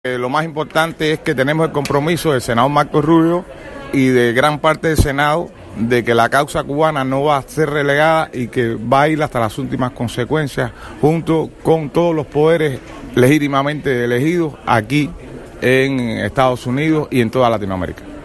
Declaraciones de Guillermo Fariñas